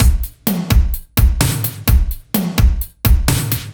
Index of /musicradar/french-house-chillout-samples/128bpm/Beats